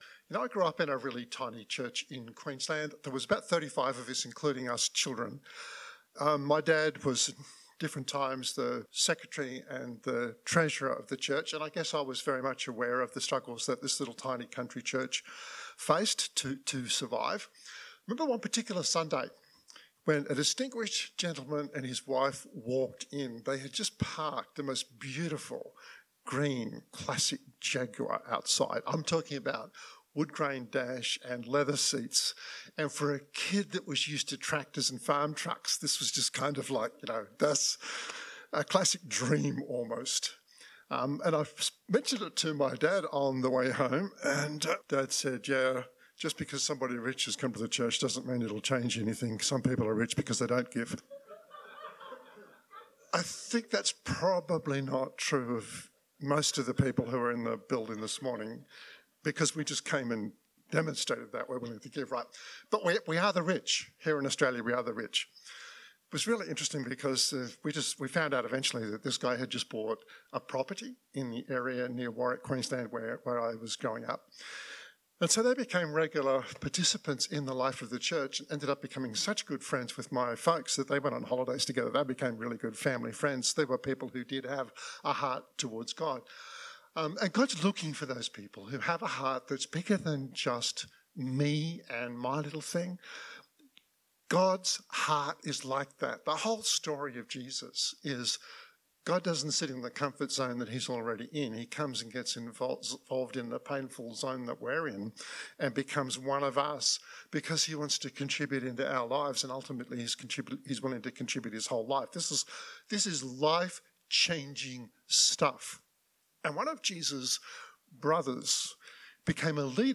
This podcast was recorded at the final gathering of Riverview Joondalup, 2022-06-26.